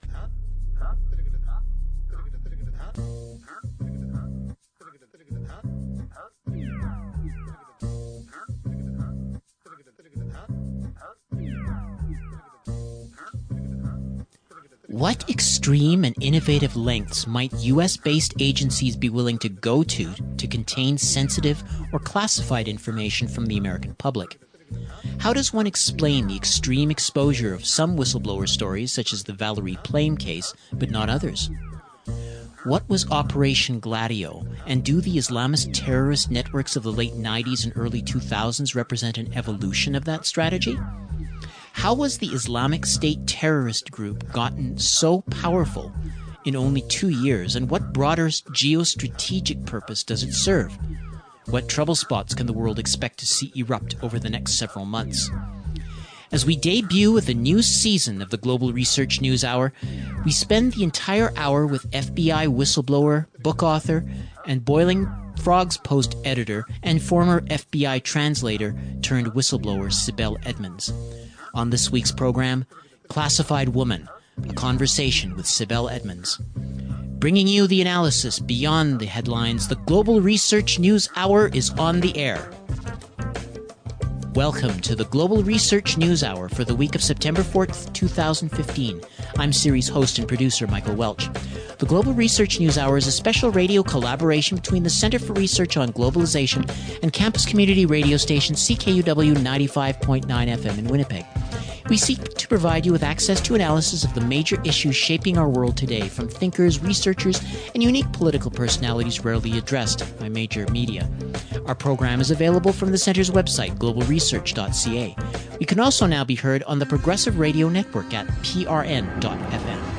Classified Woman: A conversation with Sibel Edmonds